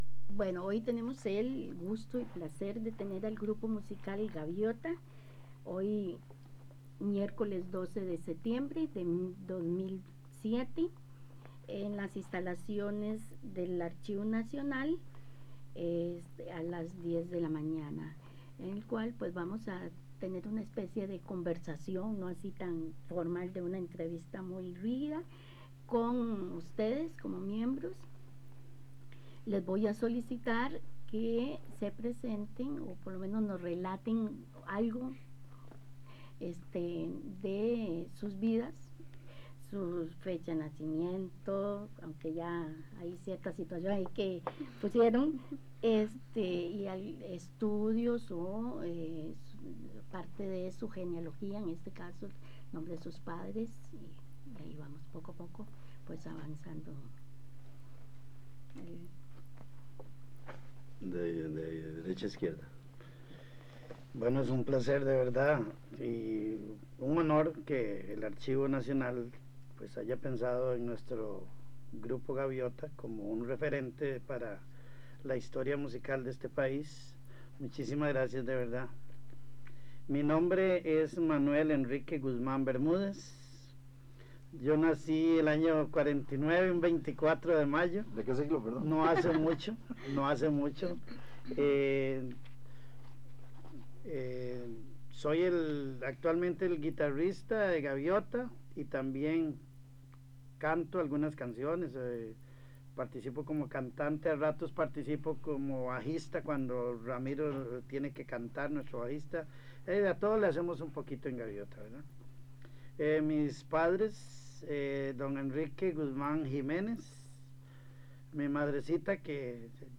Entrevista con miembros del Grupo Gaviota - Archivo Nacional de Costa Rica
Carrete abierto, casete y digital.